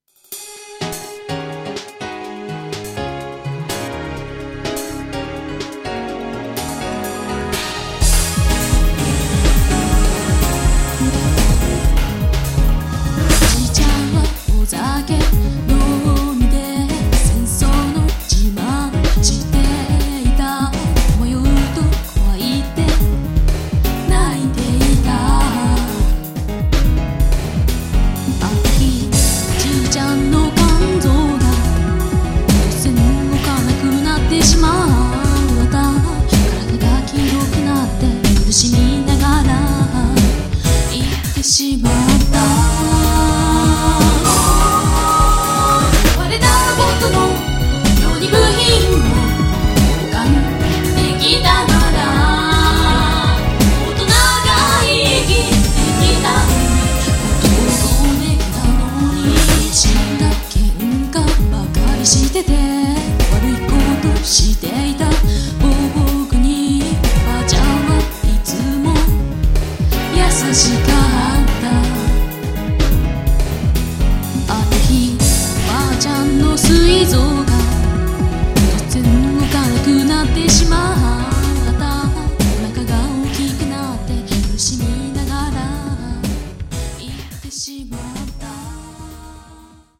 重い詞にポップな曲を乗せるという手法で、万人が受け入れやすい作品に